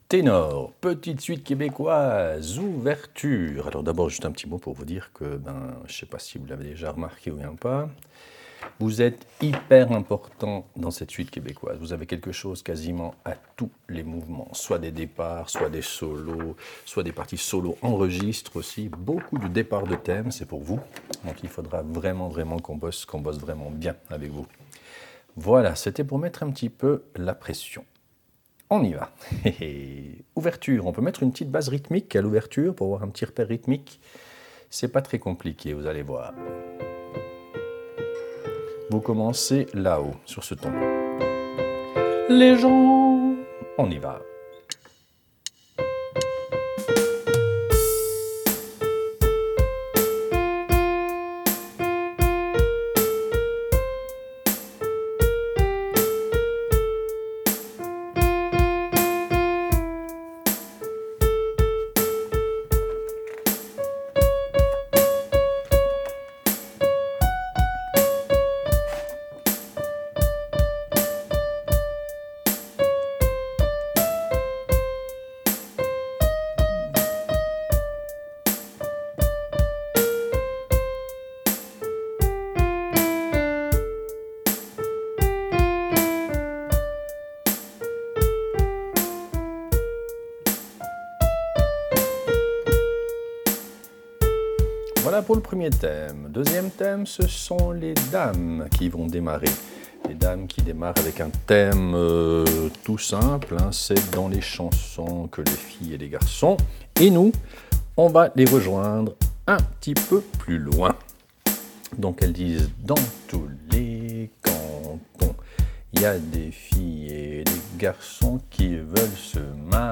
Répétition SATB4 par voix
Ténor